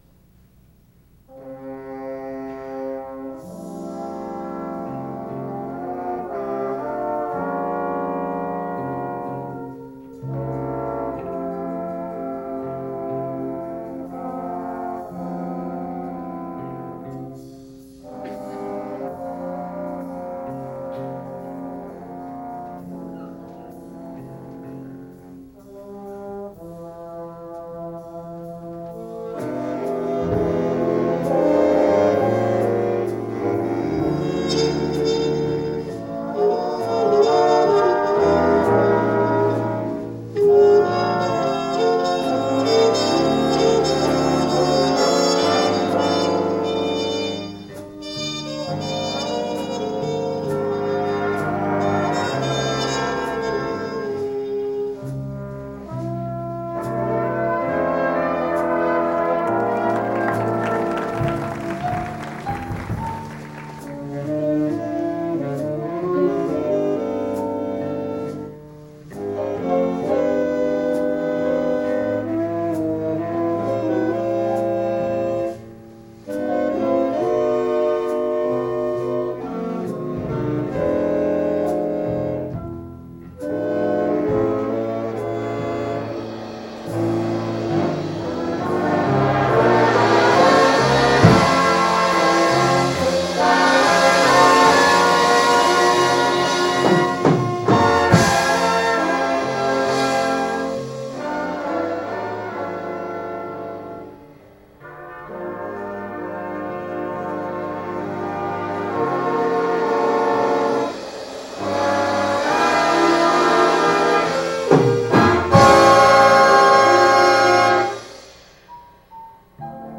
fzs_jazz_98_mmea_a_time_for_love.ogg